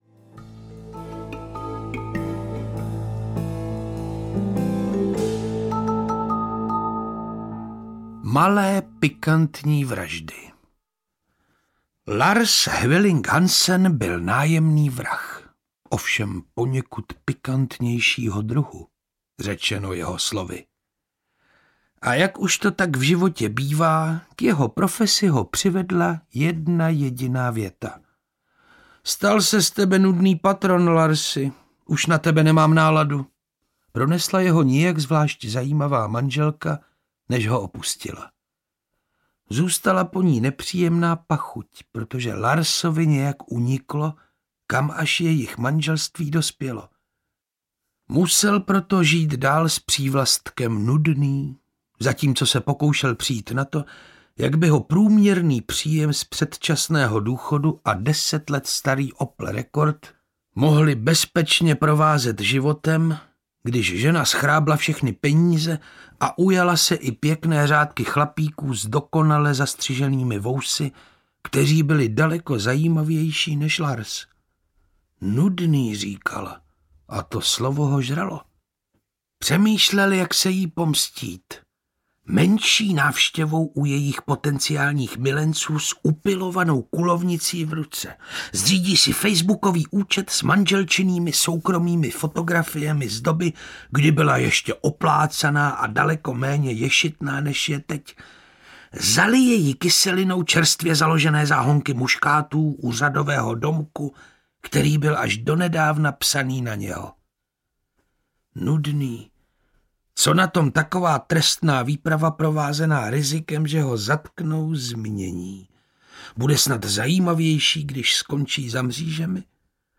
Malé pikantní vraždy audiokniha
Ukázka z knihy
• InterpretPetr Čtvrtníček